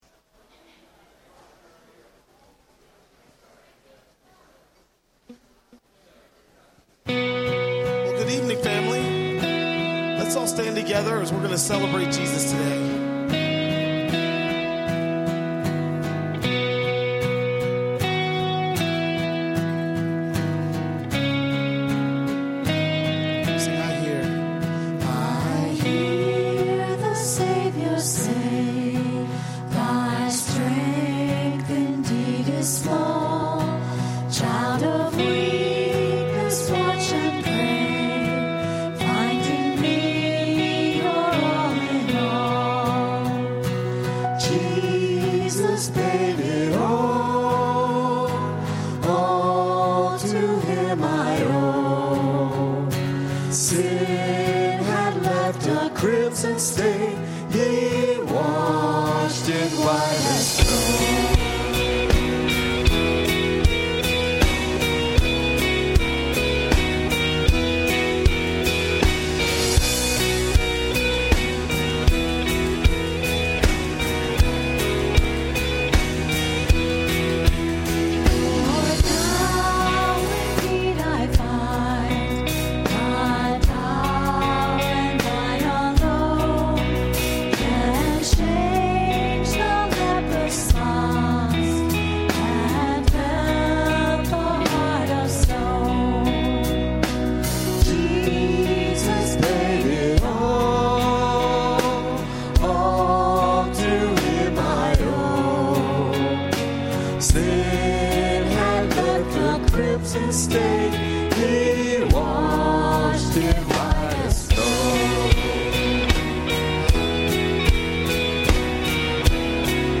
A message from the series "Ask the Pastors."